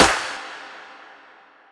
Perc 5.wav